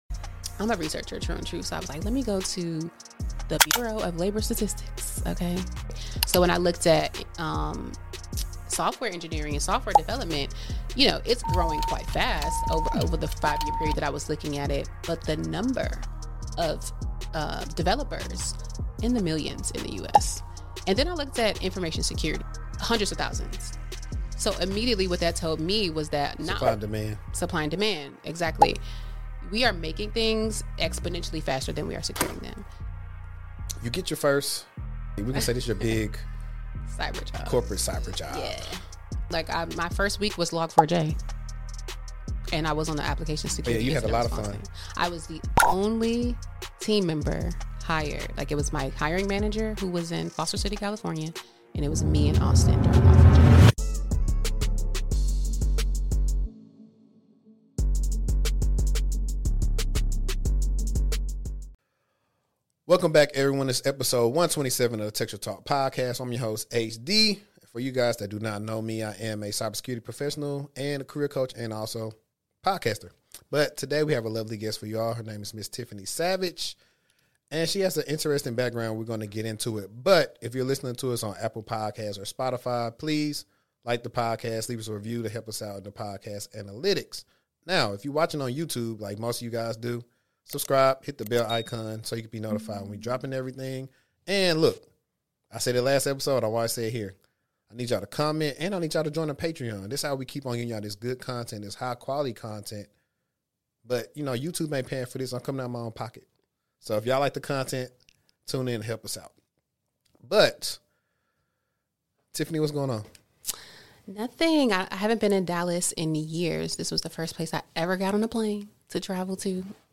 In this enlightening interview